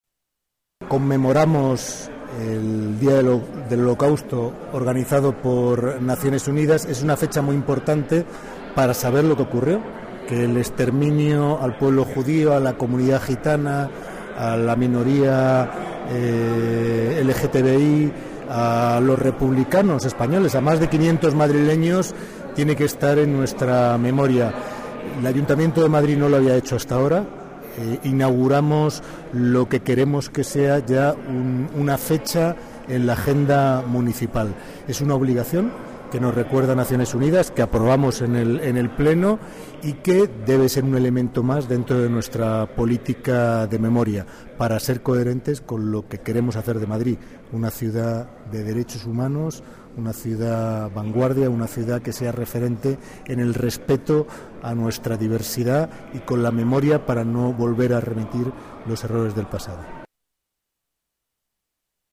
Nueva ventana:Declaraciones de Mauricio Valiente en el acto de conmemoración a las víctimas del Holocausto